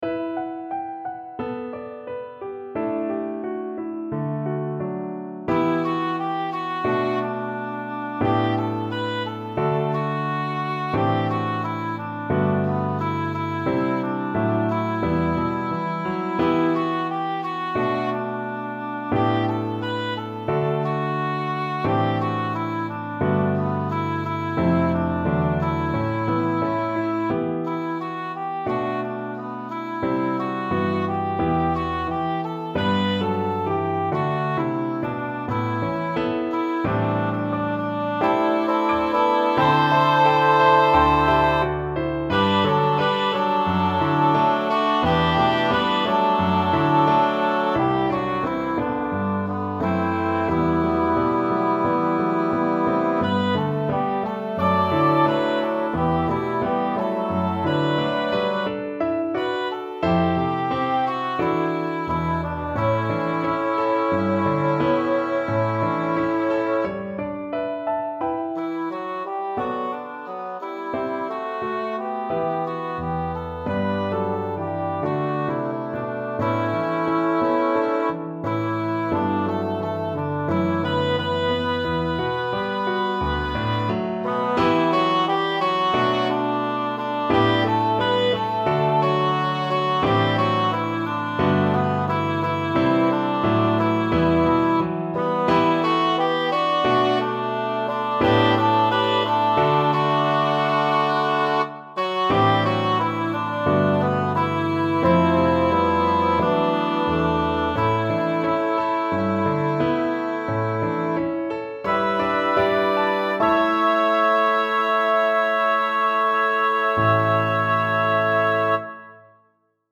SATB, alto solo, piano
A setting for congregation or choir